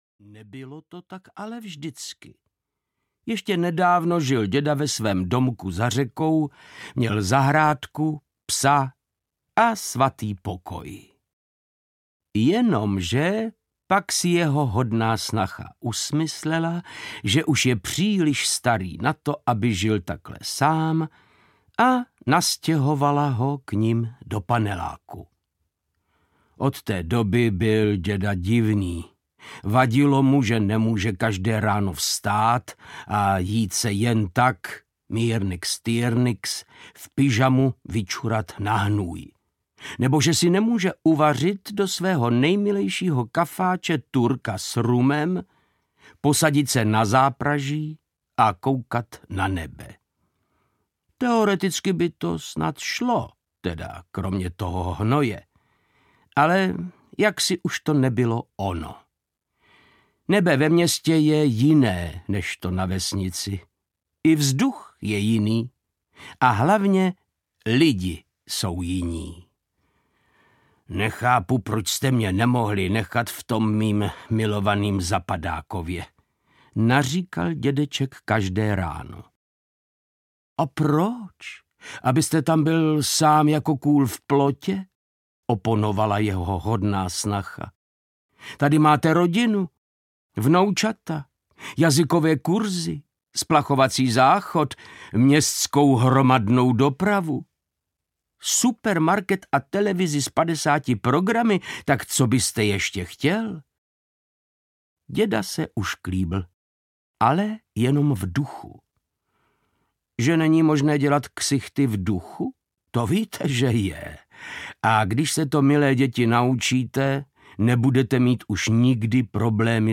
O nevyřáděném dědečkovi audiokniha
Ukázka z knihy
• InterpretVáclav Knop